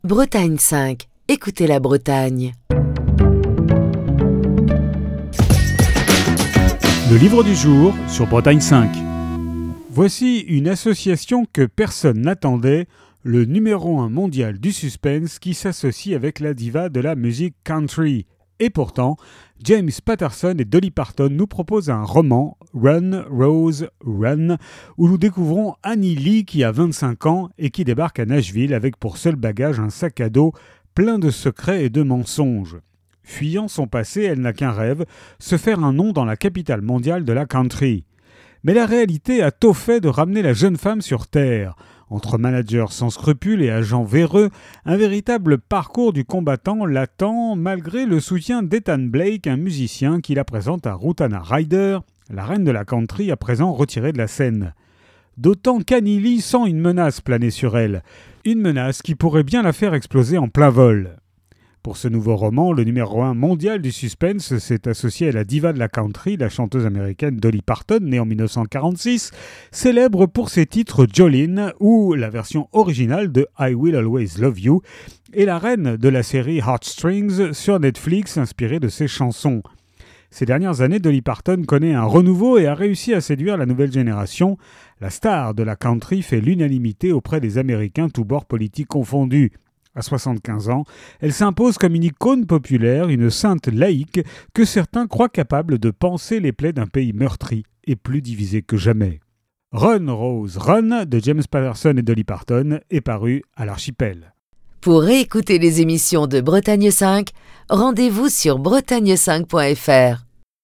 Chronique du 27 janvier 2023.